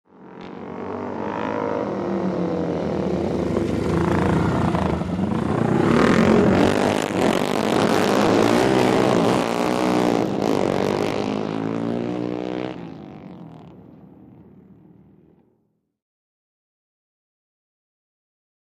Motorcycle; Several Four Stroke Dirt Bikes Through A Turn.